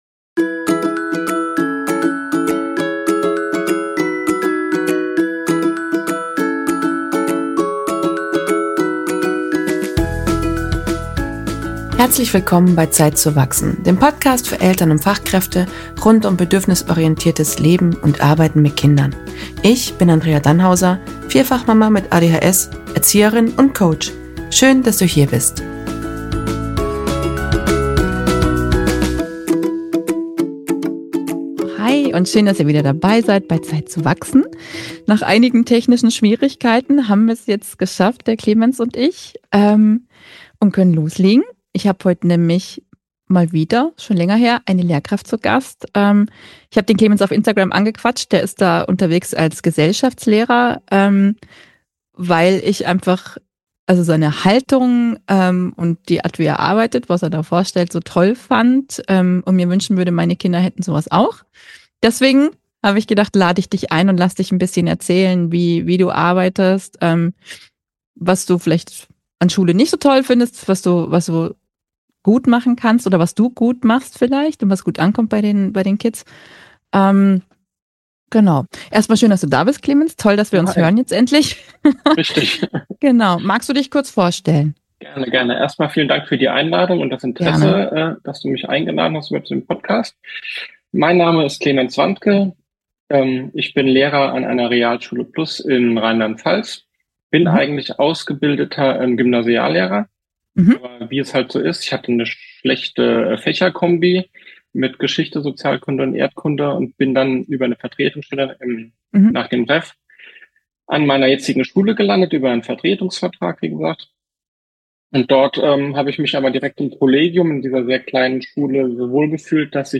In dieser Folge spreche ich mit Lehrer